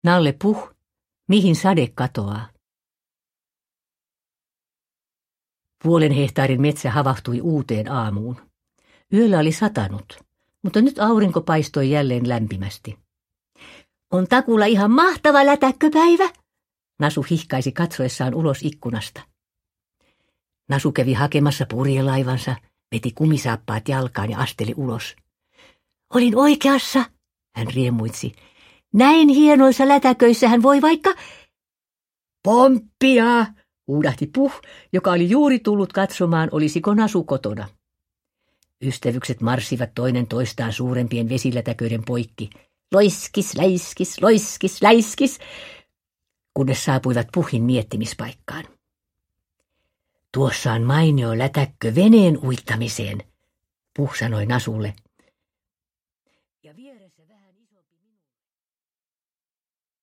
Nalle Puh. Mihin sade katoaa? – Ljudbok – Laddas ner
Uppläsare: Seela Sella